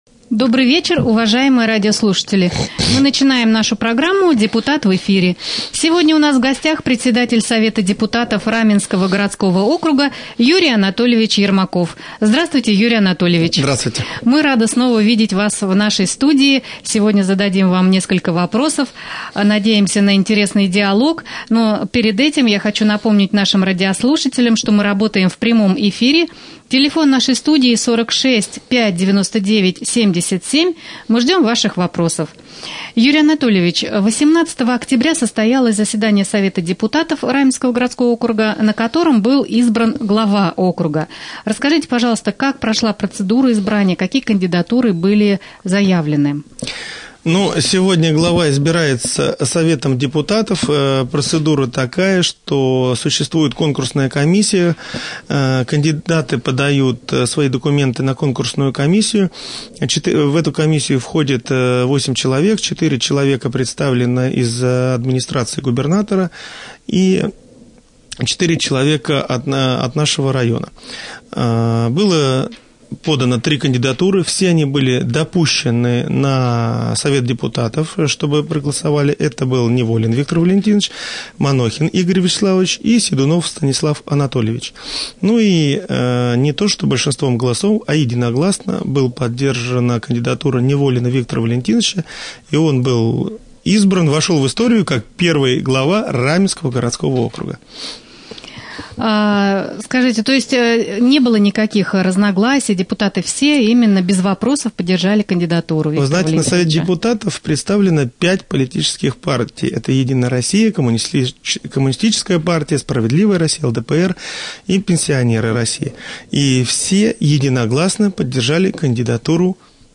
Гость студии Юрий Анатольевич Ермаков, председатель Совета депутатов Раменского городского округа.
prjamoj-jefir.mp3